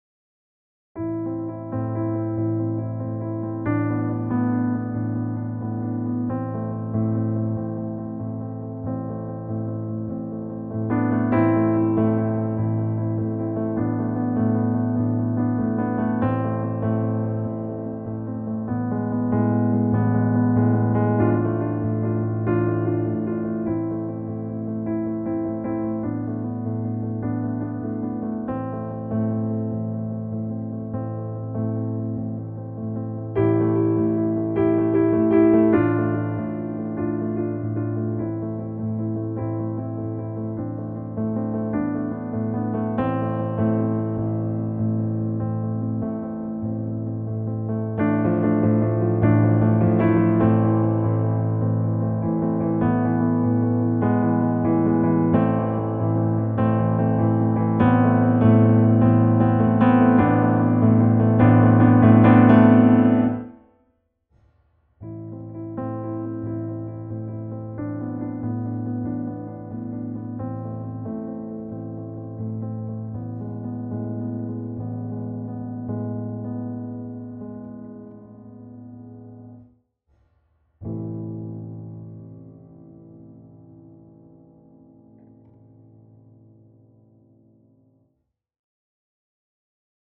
Écouter la version piano…
• Intention : Introspection, Méditation
• Tonalité : La mineur
• Mesure(s) : 4/4 (mais balancement ternaire)
• Indication(s) de Tempo : Andantino (env. ♩= 92)